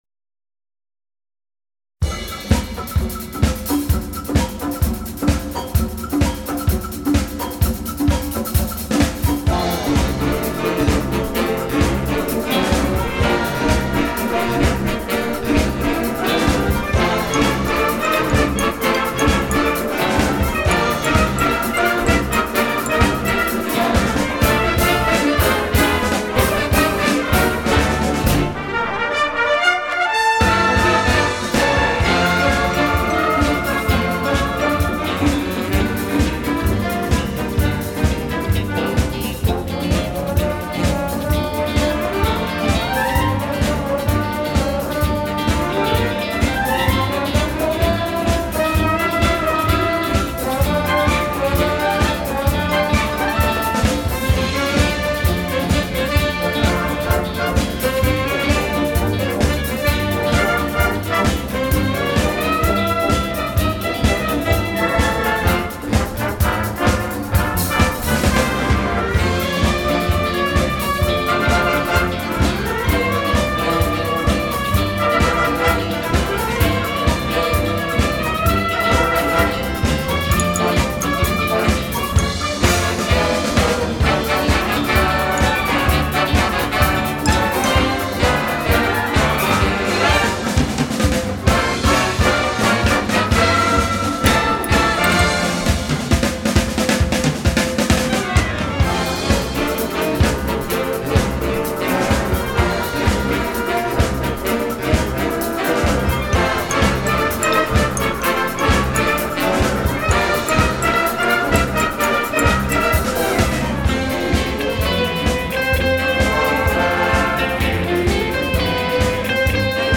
und aus jeder Ära Demotitel (live).
Blasorchester Biberach/ Kinzigtal e.V.